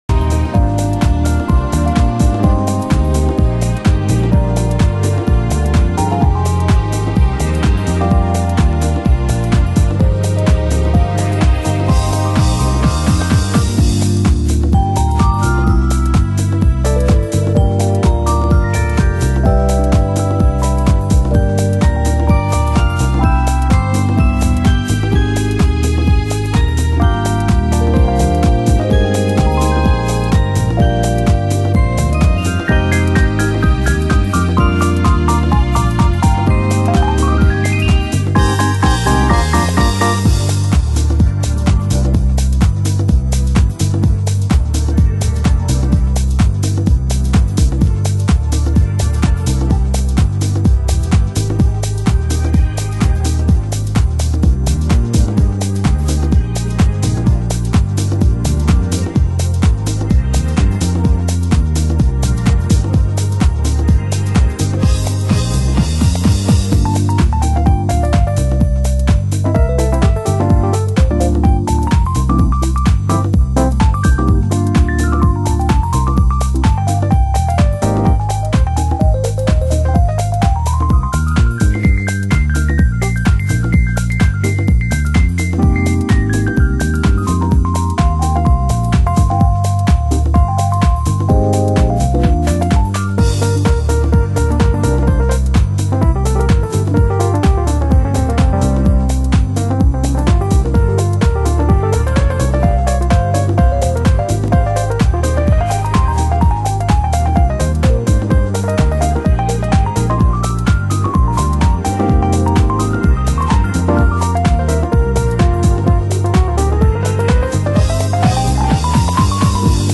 ハウス専門店KENTRECORD（ケントレコード）